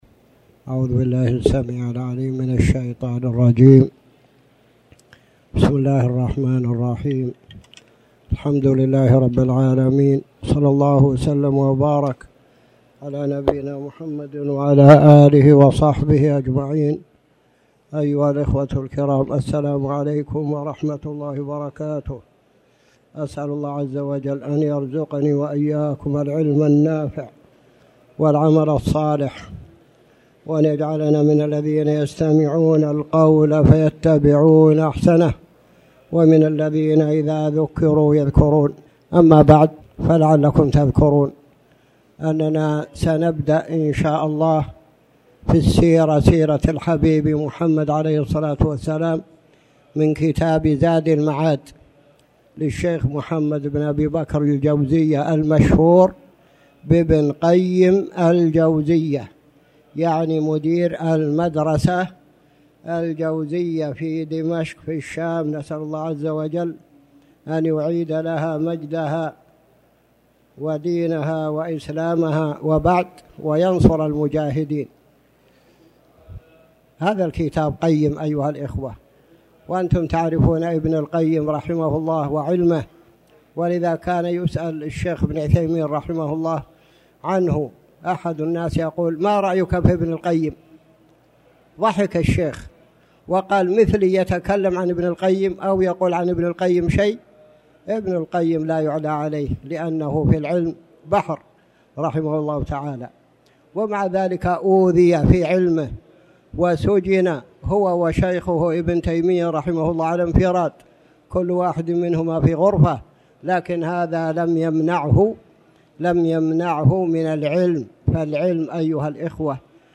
تاريخ النشر ٢٩ جمادى الآخرة ١٤٣٩ هـ المكان: المسجد الحرام الشيخ